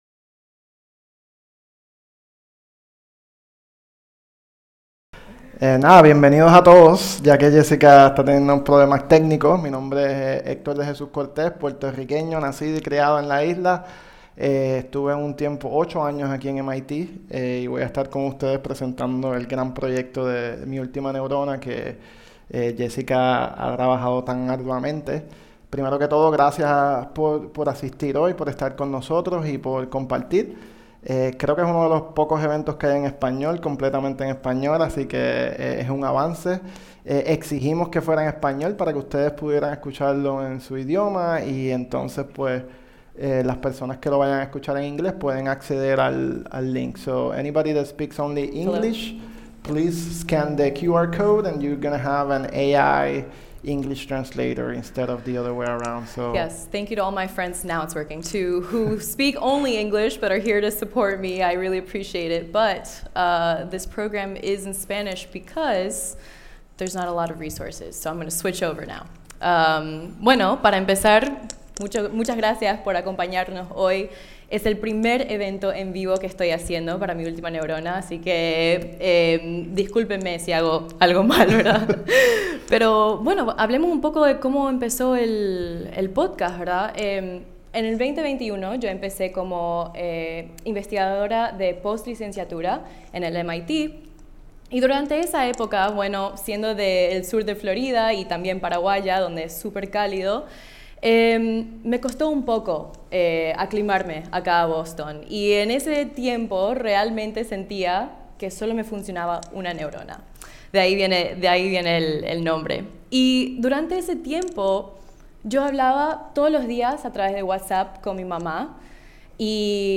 Revive nuestro primer evento en vivo de Mi Última Neurona, realizado el pasado 25 de septiembre en el MIT Museum, como parte del Cambridge Science Festival.